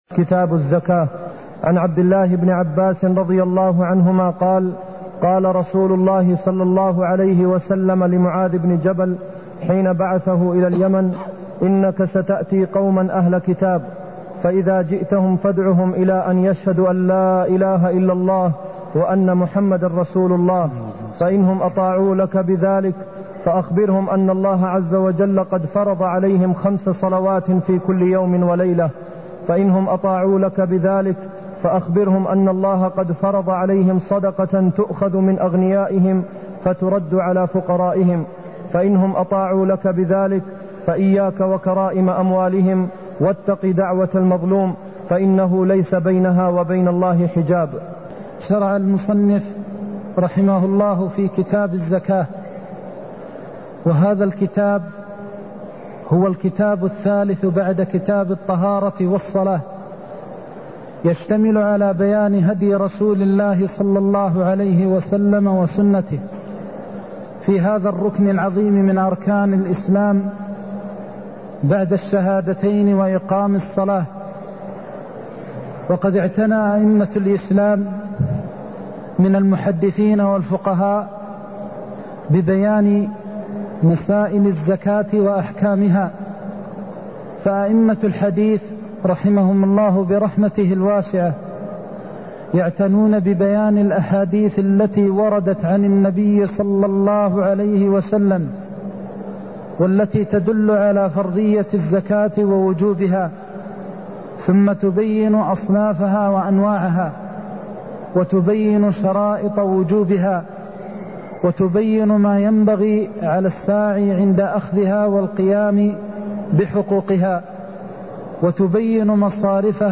المكان: المسجد النبوي الشيخ: فضيلة الشيخ د. محمد بن محمد المختار فضيلة الشيخ د. محمد بن محمد المختار إنك ستأتي قوم أهل كتاب (162)